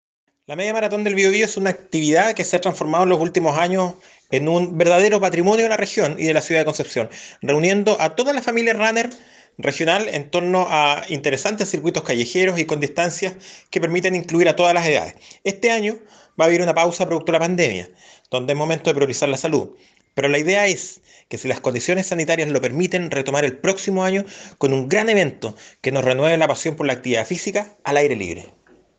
Marco Loyola, SEREMI del Deporte